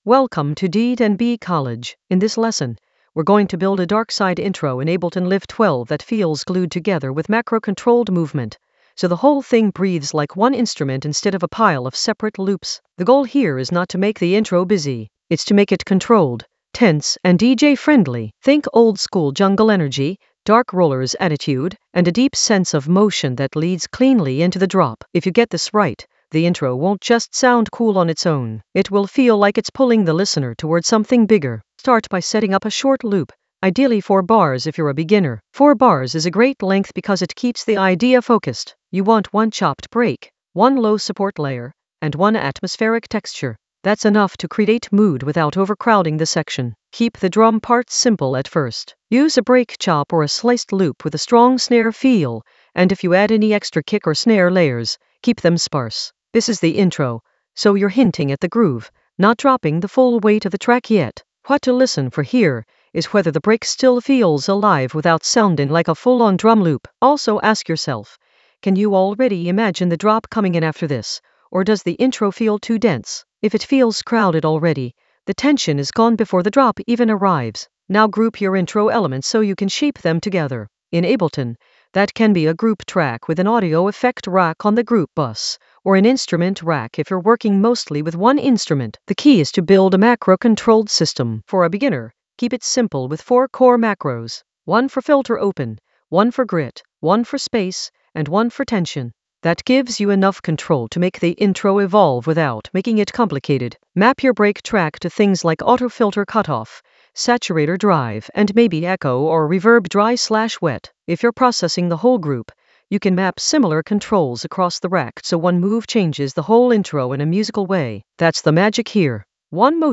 An AI-generated beginner Ableton lesson focused on Glue a darkside intro using macro controls creatively in Ableton Live 12 for jungle oldskool DnB vibes in the Drums area of drum and bass production.
Narrated lesson audio
The voice track includes the tutorial plus extra teacher commentary.